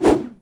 HandSwing4.wav